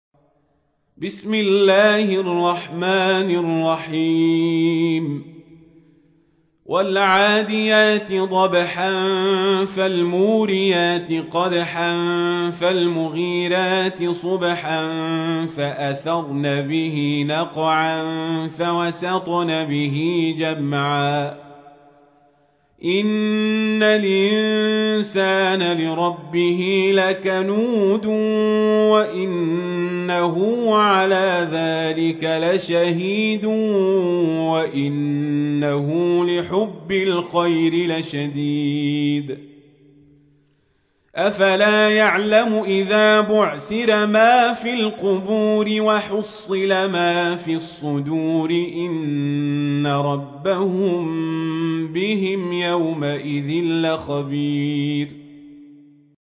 تحميل : 100. سورة العاديات / القارئ القزابري / القرآن الكريم / موقع يا حسين